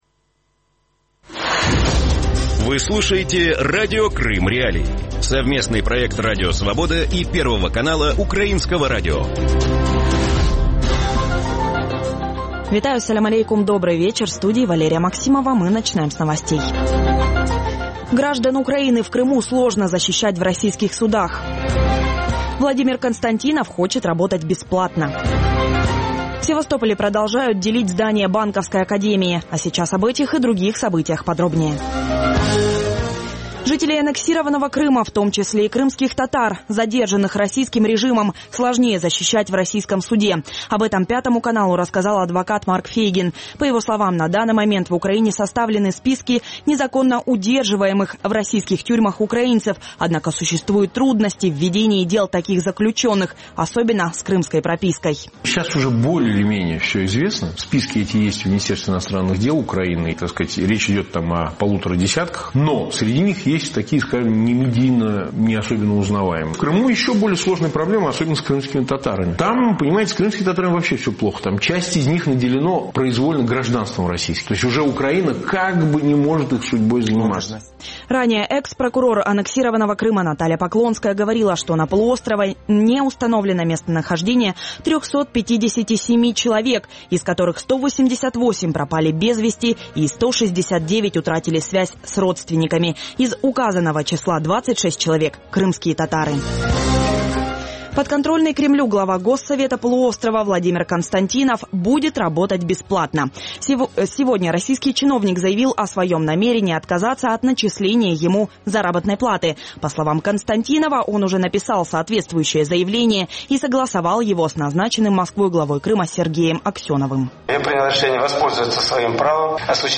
Вечерний выпуск новостей о событиях в Крыму. Все самое важное, что случилось к этому часу на полуострове.